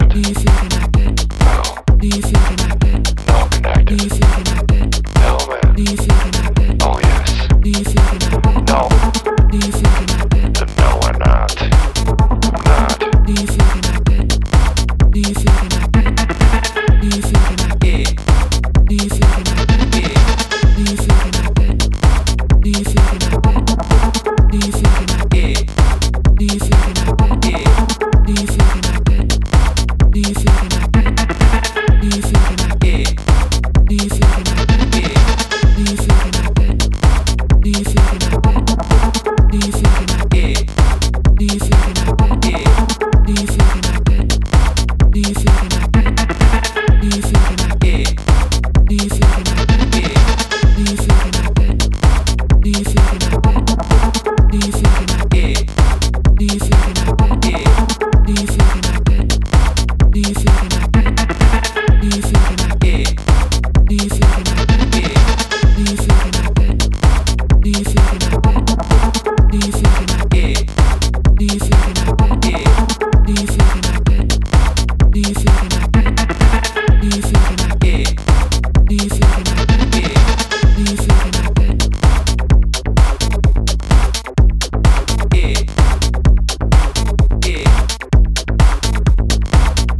EBM的強迫ベースとスリリングなリフがピークタイム仕様な